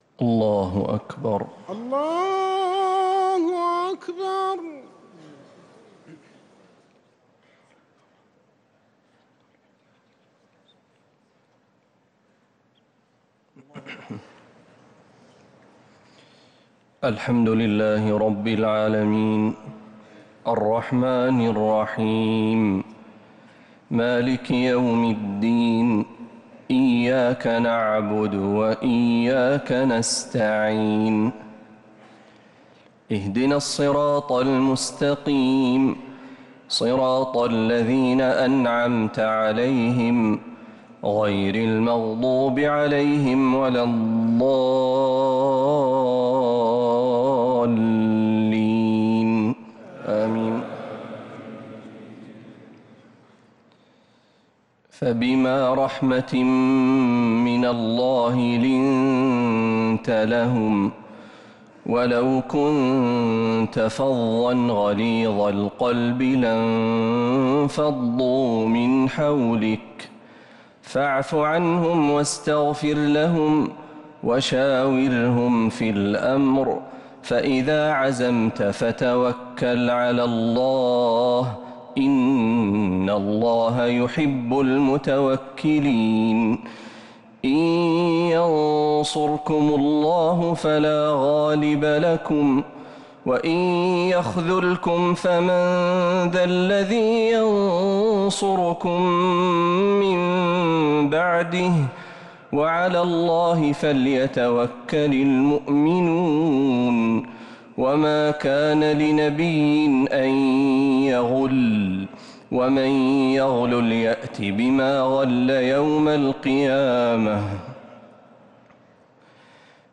تراويح ليلة 5 رمضان 1447هـ من سورة آل عمران (159-200) | Taraweeh 5th night Ramadan 1447H Surah Aal-i-Imraan > تراويح الحرم النبوي عام 1447 🕌 > التراويح - تلاوات الحرمين